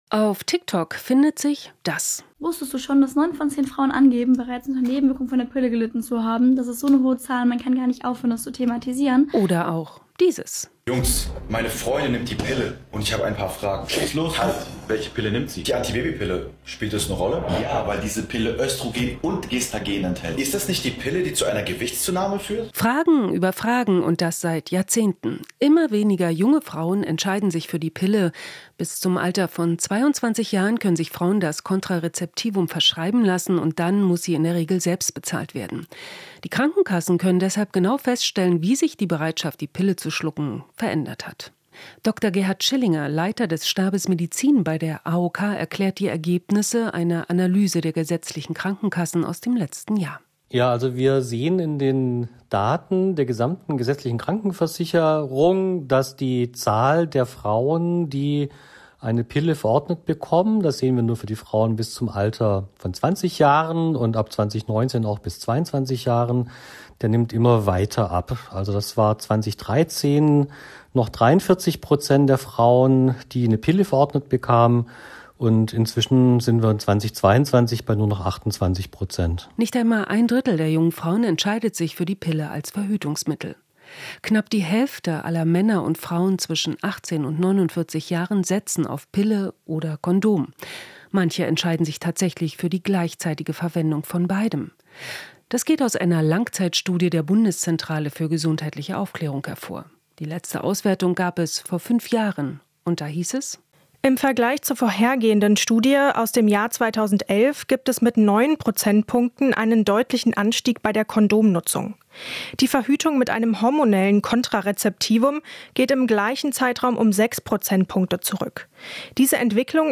Inforadio Nachrichten, 18.08.2023, 14:20 Uhr - 18.08.2023